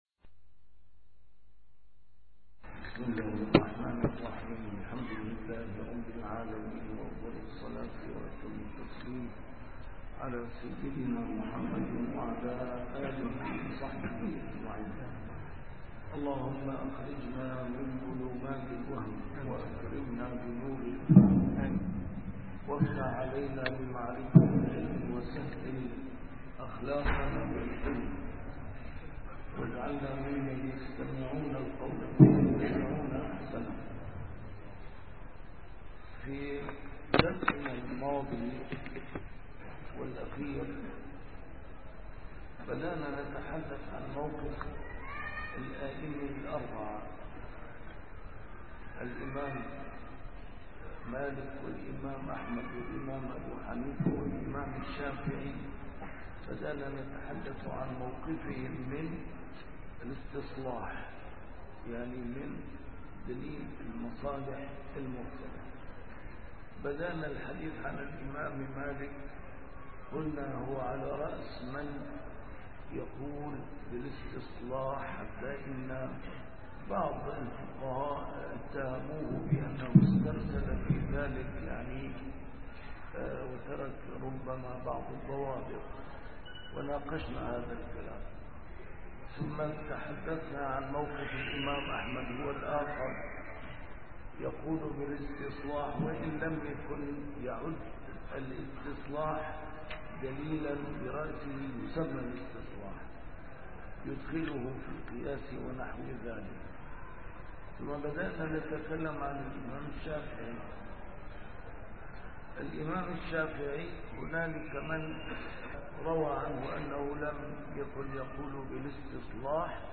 A MARTYR SCHOLAR: IMAM MUHAMMAD SAEED RAMADAN AL-BOUTI - الدروس العلمية - ضوابط المصلحة في الشريعة الإسلامية - موقف أئمة المذاهب 3- الإمام الشافعي (323-326)